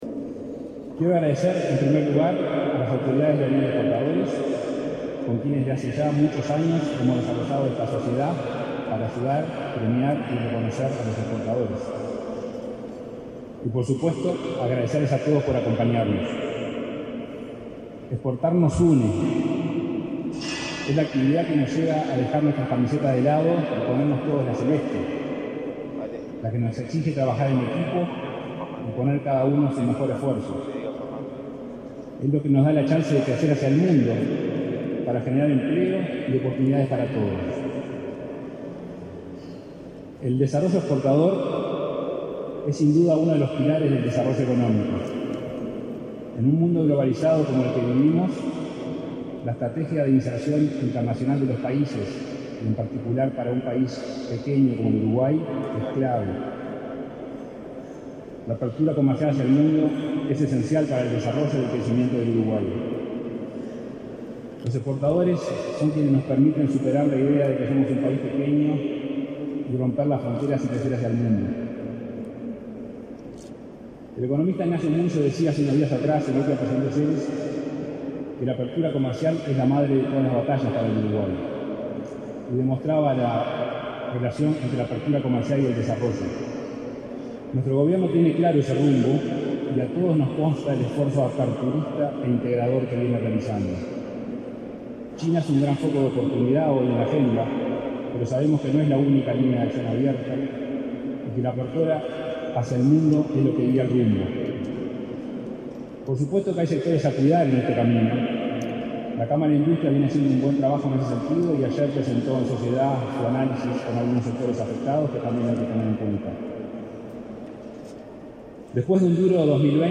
Palabras del presidente del Banco República, Salvador Ferrer
Palabras del presidente del Banco República, Salvador Ferrer 09/12/2021 Compartir Facebook X Copiar enlace WhatsApp LinkedIn Con la presencia del presidente de la República, Luis Lacalle Pou, el Banco República y la Unión de Exportadores entregaron, este 8 de diciembre, los reconocimientos al esfuerzo exportador 2021. Ferrer fue el orador del acto.